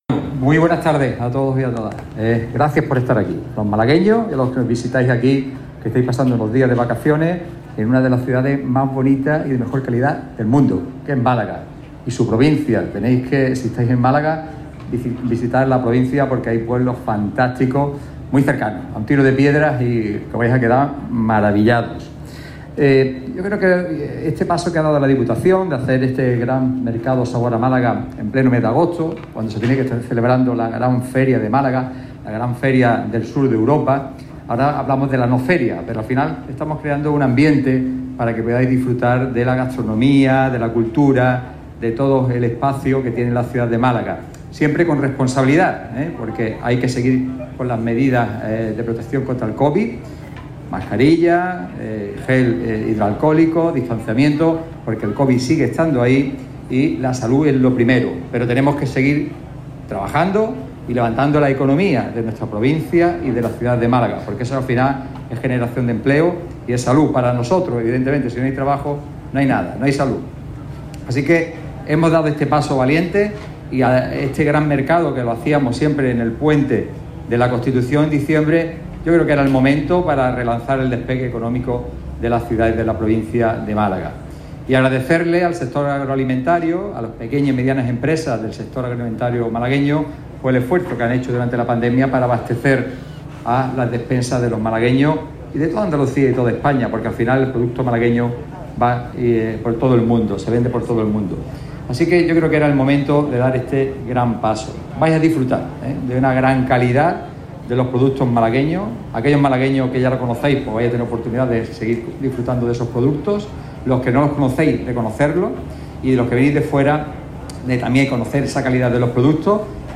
Tras el recorrido oficial y sobre el mismo escenario instalado en la Plaza de la Marina, donde las autoridades han realizado el acto de presentación, el presidente de la Diputación Provincial, Francisco Salado ha destacado el desarrollo de este mercado como “una forma de apoyar a nuestros productores y a nuestros restauradores, tan importantes para la economía y el empleo de nuestra provincia, pero también como una manera de disfrutar de lo nuestro, de lo malagueño, en una feria diferente, que esperemos sea la última en estas circunstancias”.
A-SALADO-INAUGURACION-MERCADO-SABOR-A-MALAGA.mp3